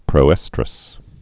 (prō-ĕstrəs)